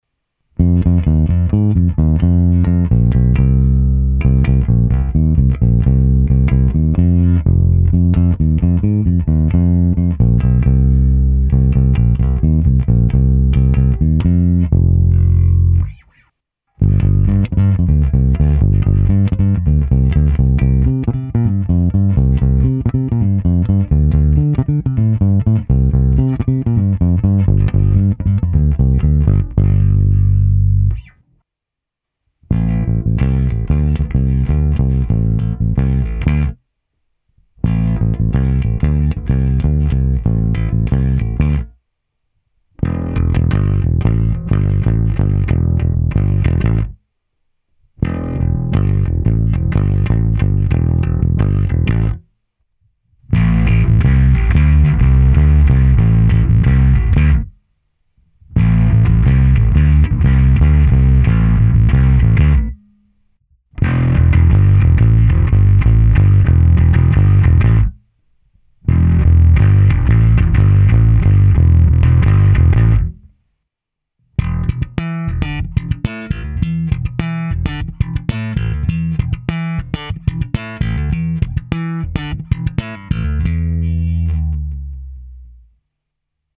Pro představu, jak hraje baskytara přes aparát, jsem ji prohnal kompresorem TC Electronic SpectraComp a preampem Darkglass Aplha Omega Ultra se zapnutou simulací aparátu snímaného mikrofony. Nahrávka je v pořadí: ukázka 1, ukázka 2, agresívní rockové hraní, agresívní rockové hraní se zkreslením a slap se zapnutím mid-scoop ekvalizéru.
Nahrávka přes Darkglass